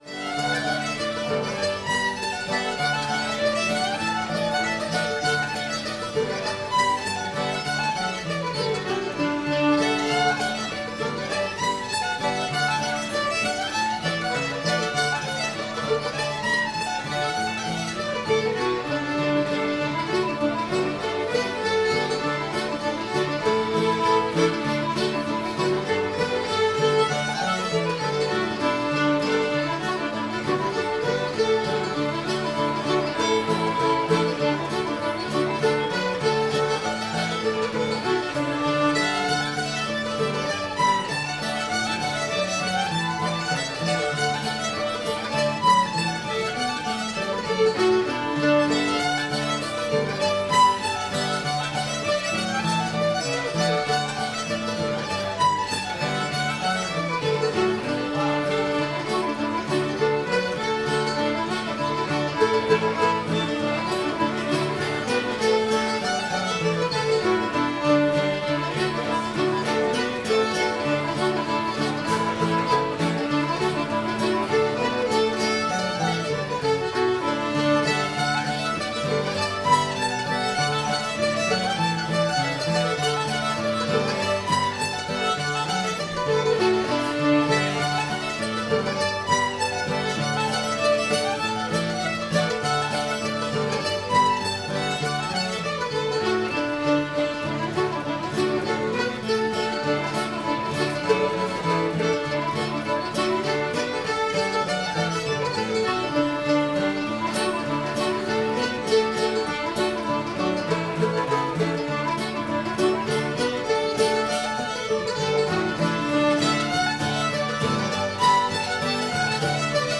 lafayette [D]